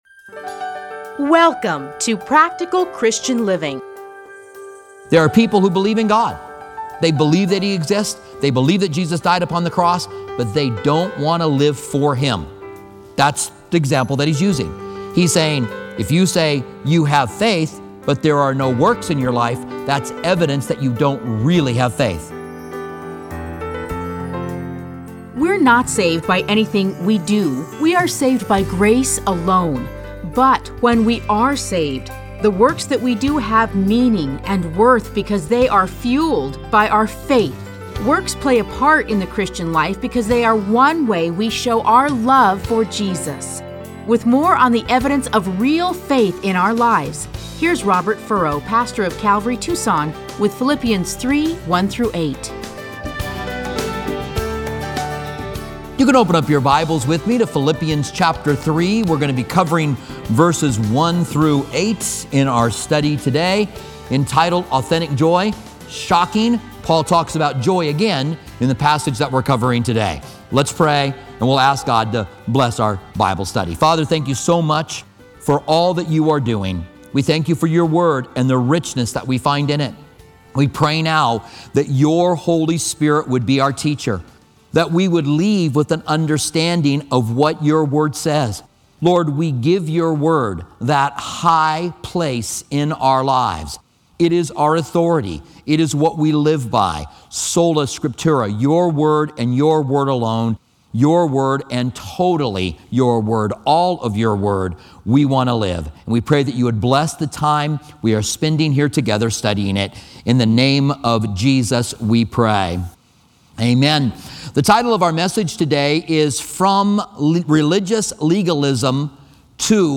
Listen to a teaching from A Study in Philippians 3:1-8.